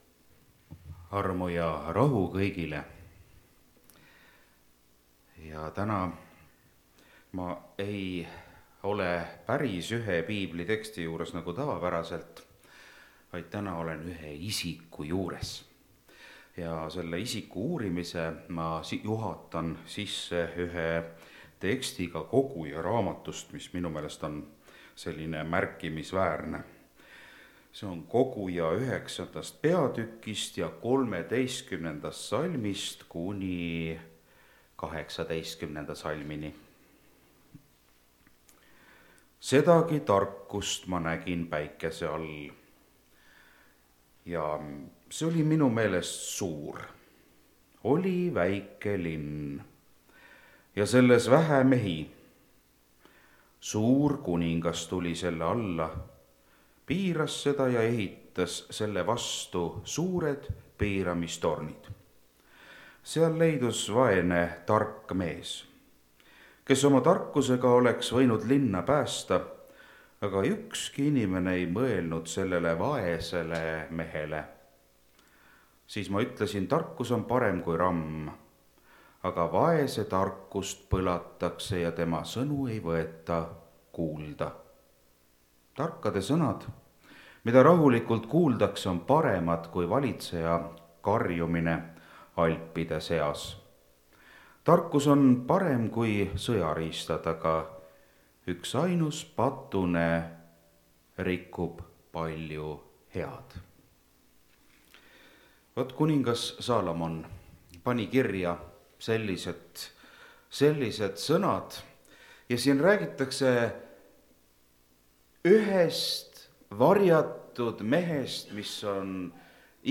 Kaotatud tarkus (Rakveres)
Jutlused